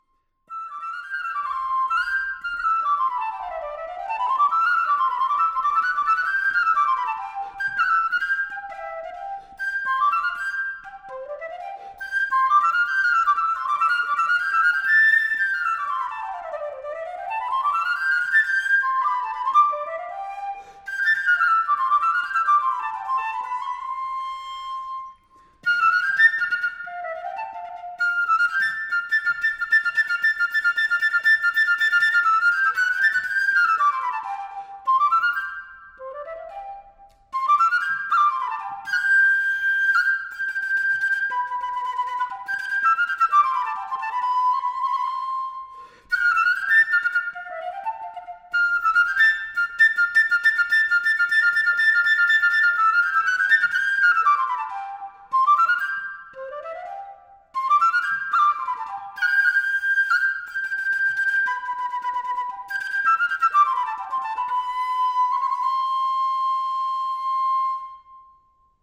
flûte & musette